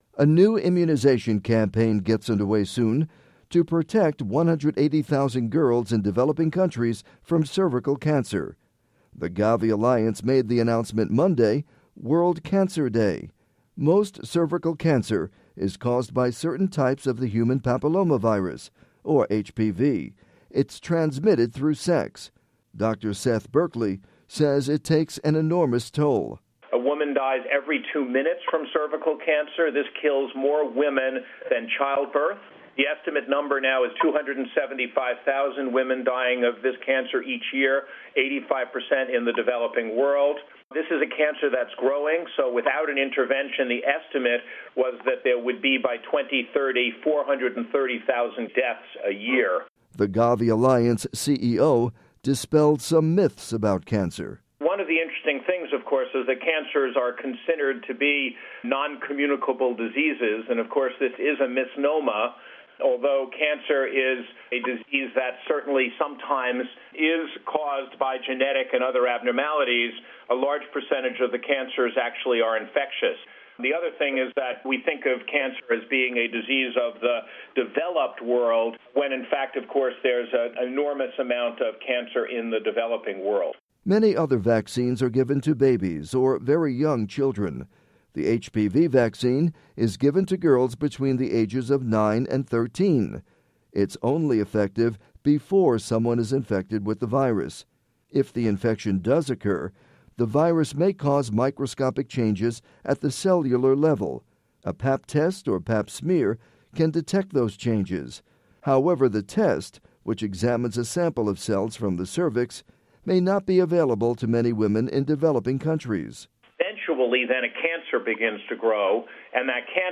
Dr. Seth Berkley, CEO, GAVI Alliance (Photo: GAVI)